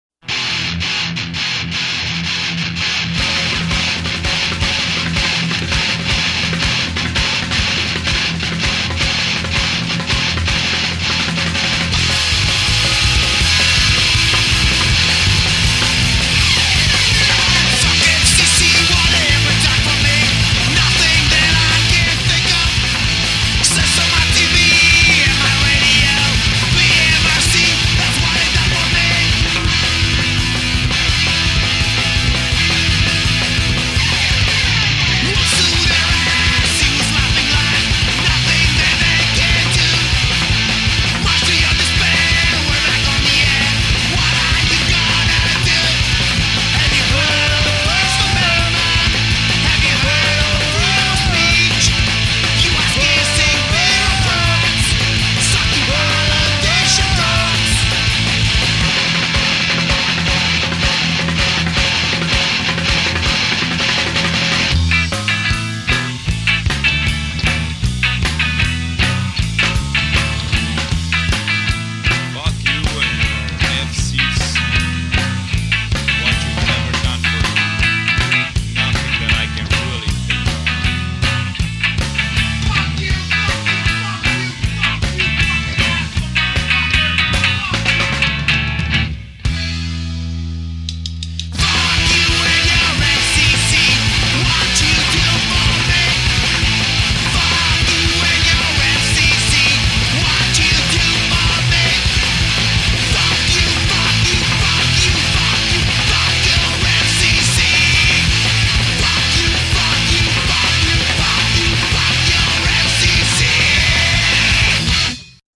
doin' it DIY-style